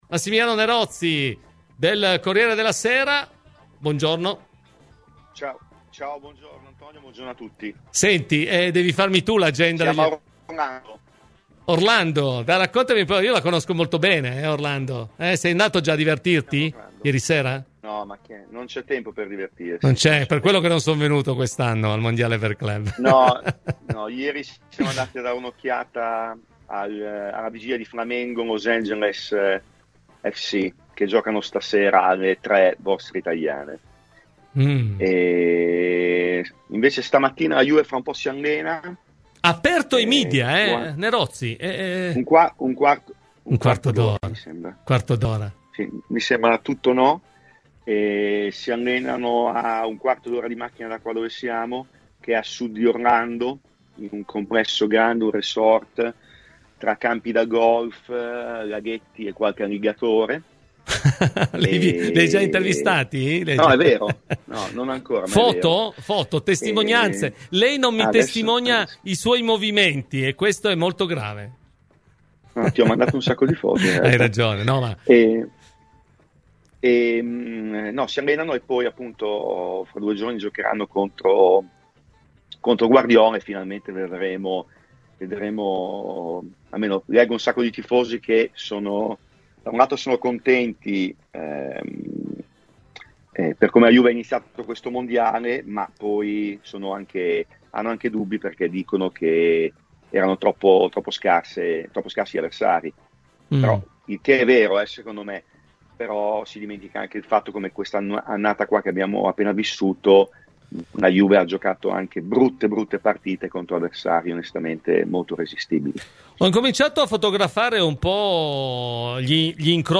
ha parlato in diretta dagli Stati Uniti dei temi del giorno legati al Mondiale per club